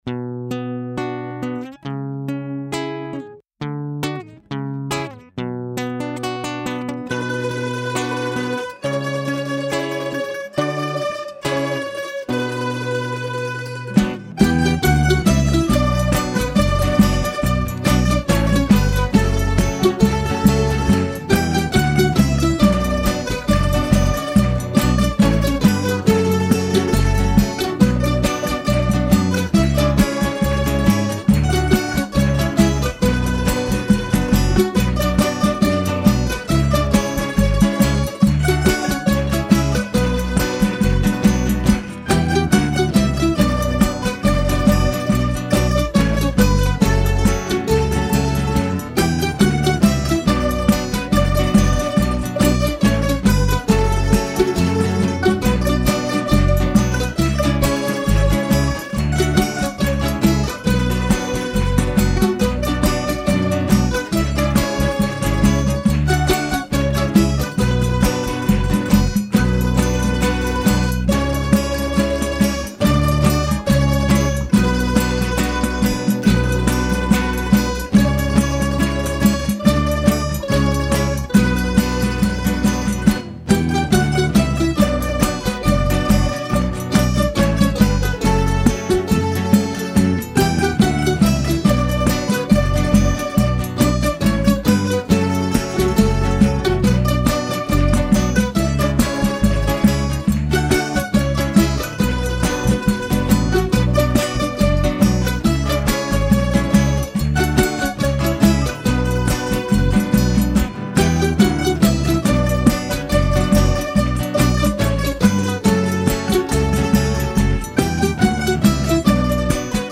1224   02:49:00   Faixa:     Xote